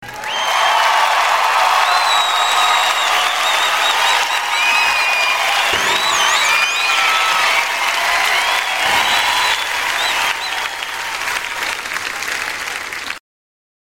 Звуки аплодисментов
Поздравления с первым местом аплодисменты победителю